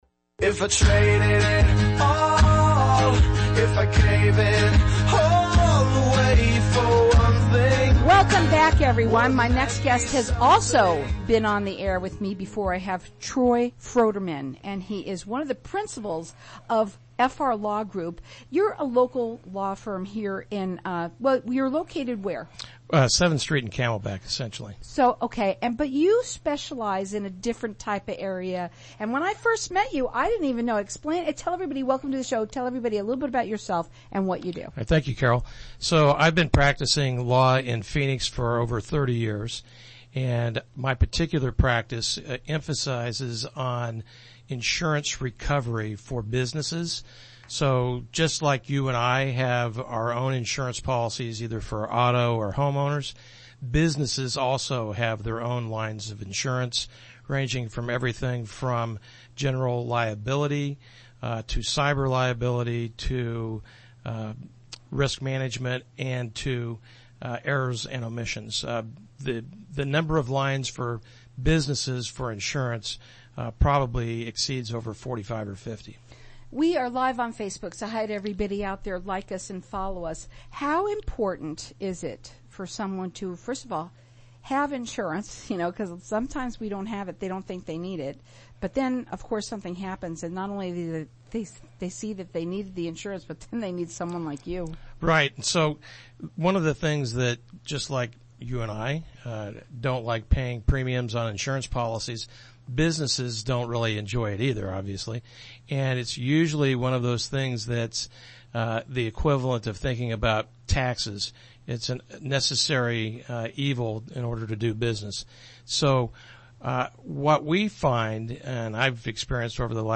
How to choose best type of insurance coverage for a business or company - Radio Guesting August 10, 2020 - FR Law Group PLLC
Aug10radioshowguesting.mp3